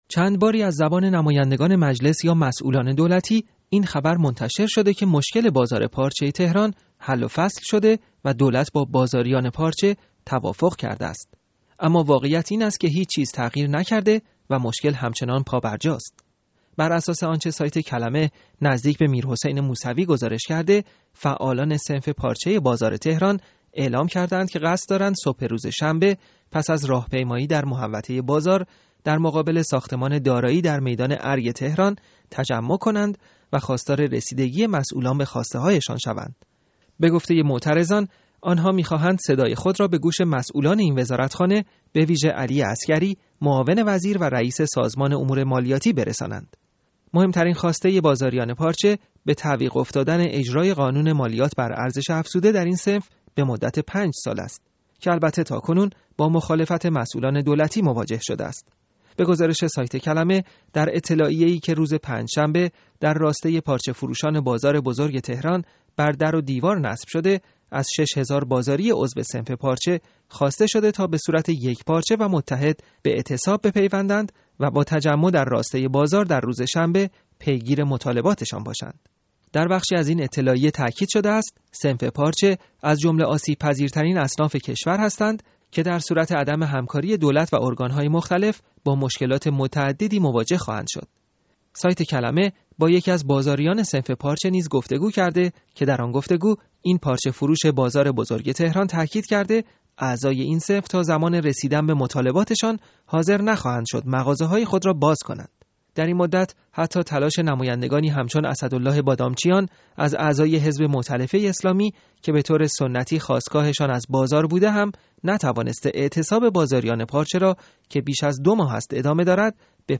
گزارش ادامه اعتراض در بازار را بشنوید